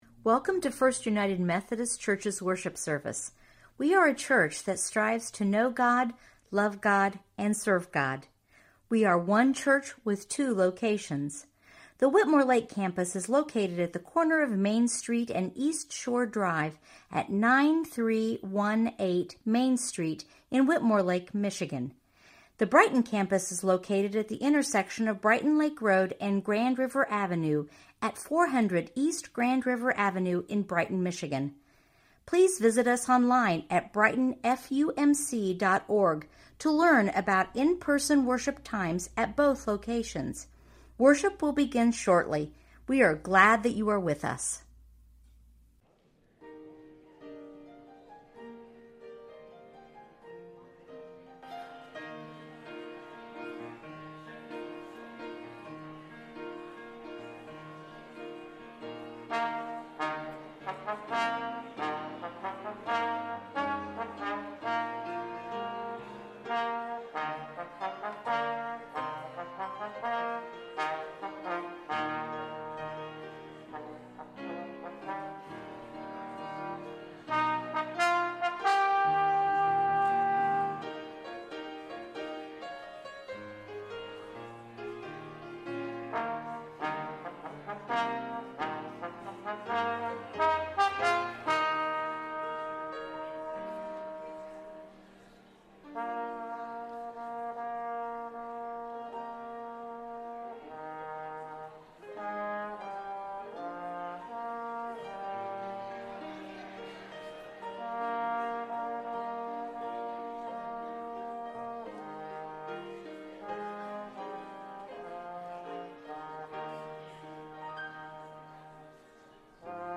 preaches on John 20:1-18